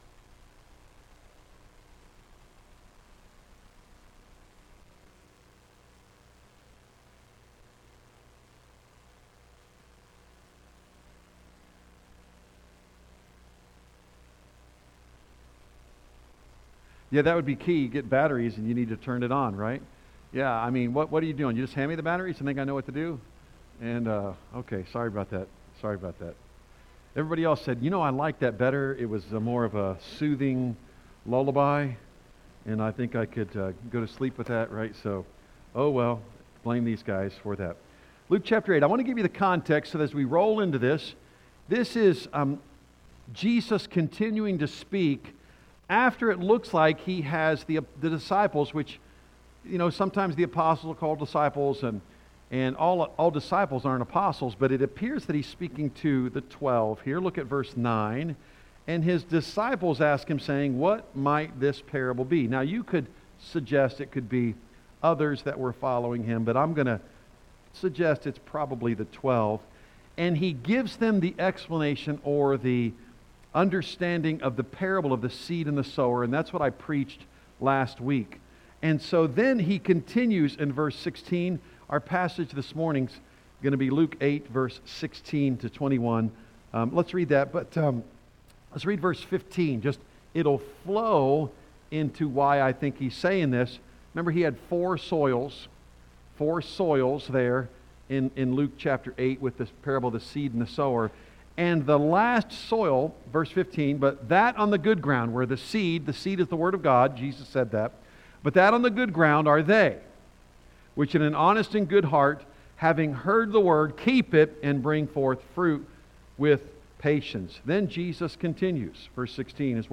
A message from the series "Luke."